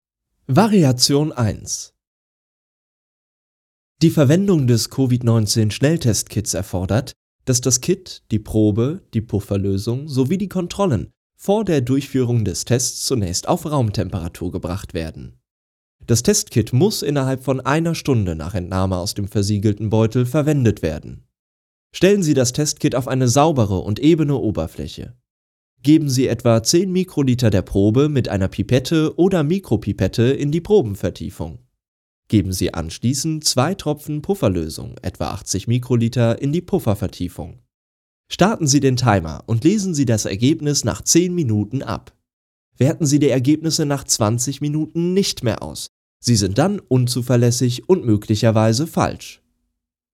Sprechprobe: Industrie (Muttersprache):
Noted for his authentic, friendly & warm personas. A versatile performer with a variety of styles.